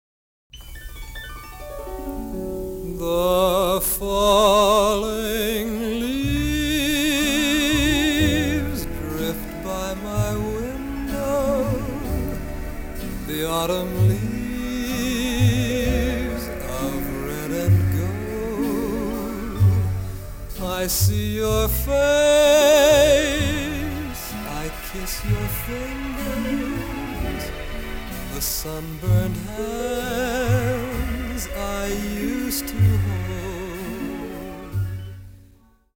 Two remastered Hi-Fi LPs
along with a small combo.